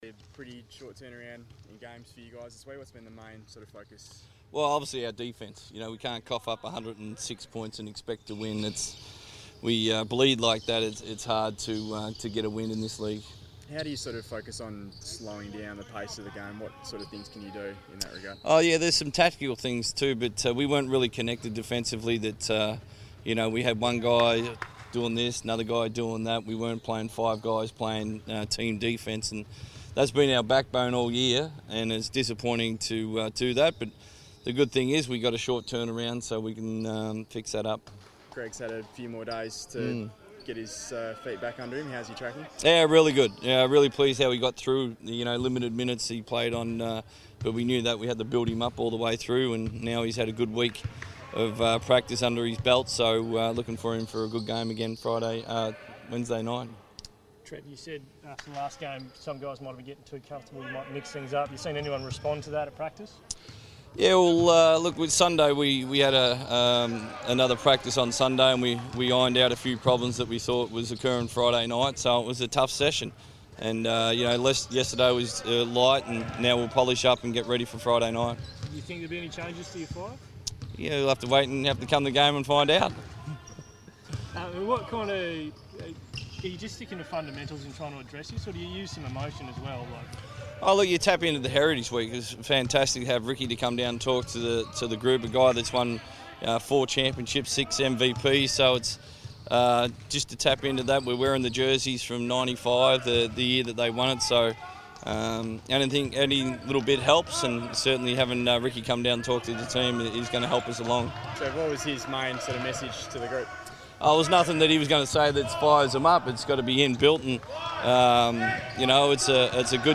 press conference